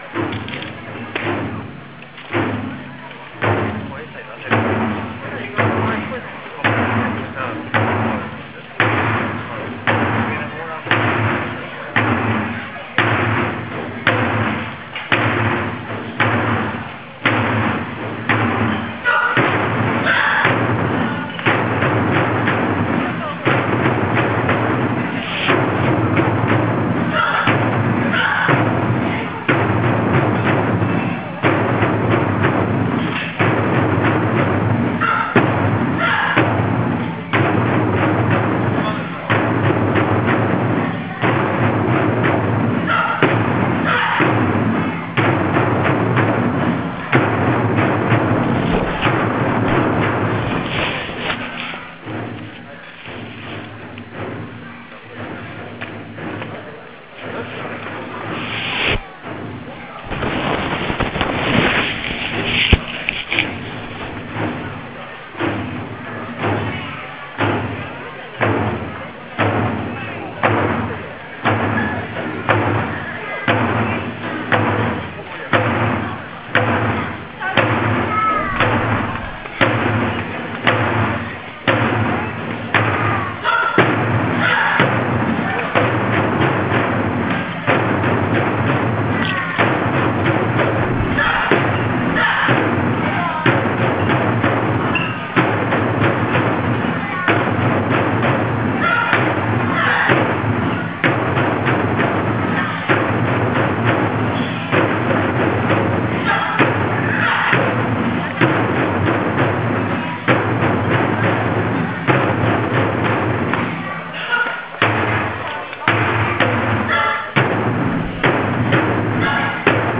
第６回東淀川ドコドコドンが、１１月３０日(土)に西淡路小学校で開催されました。たいへん寒い日になりましたが、出演者の情熱に、また、太鼓の響きに会場が熱気に包まれた１日になりました。
本校からは、５者活動の時間を中心に練習を重ねた８人が出演し、
メンバーの中には練習を始めて、まだ、２ヶ月の１年生もいました。